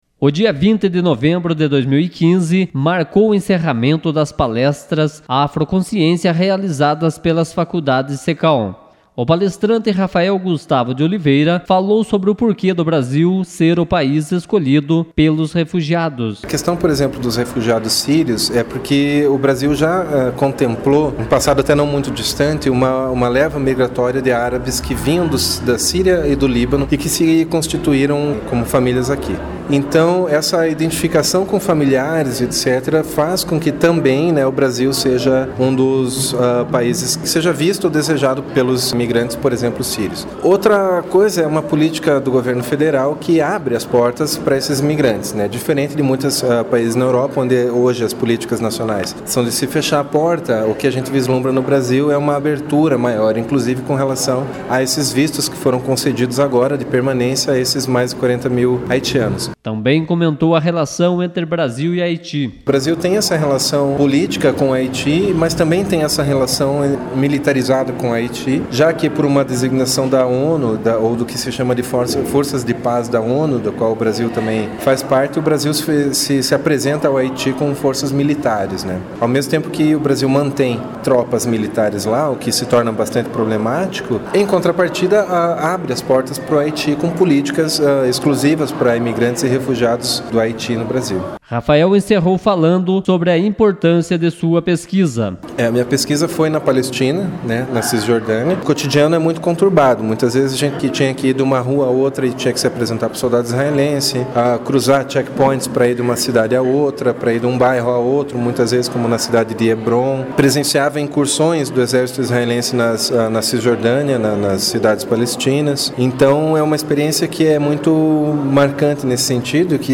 Radiojornalismo: palestra aborda sobre a situação dos refugiados
Palestra-Xenofobia-e-refugiados.mp3